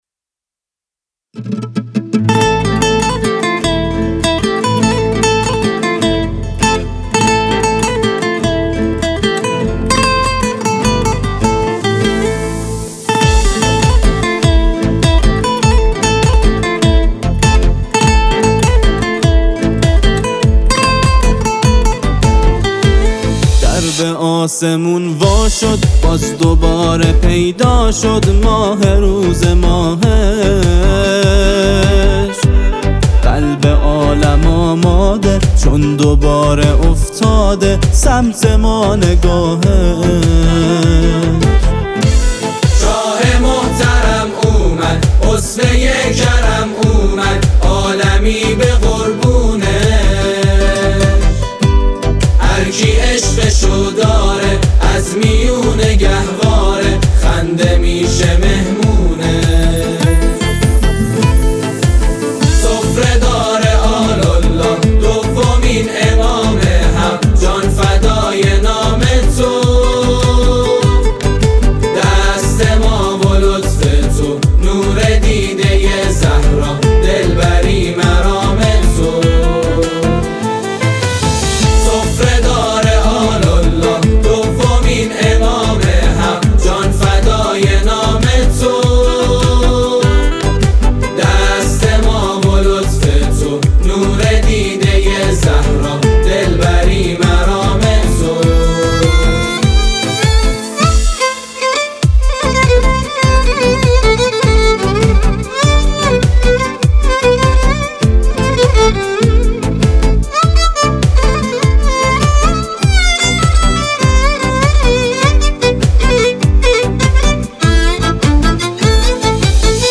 اجرای گروه هم آوایی نجوا